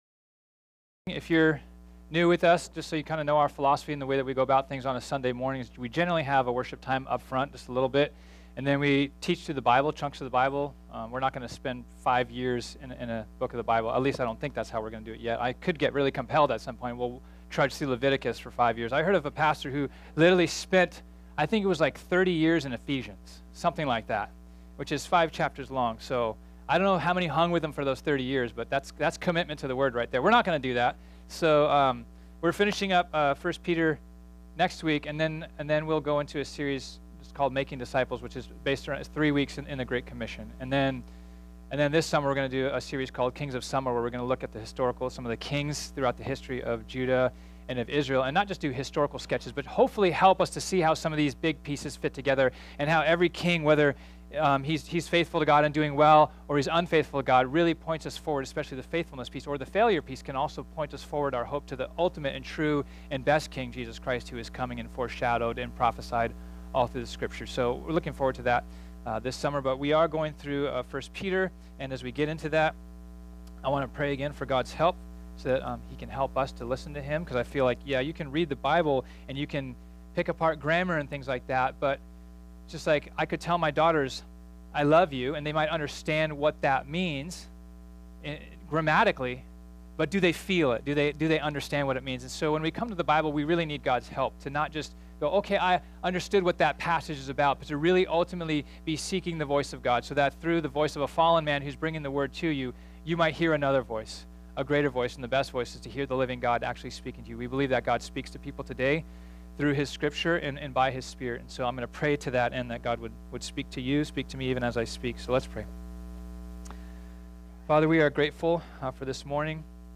This sermon was originally preached on Sunday, April 22, 2018.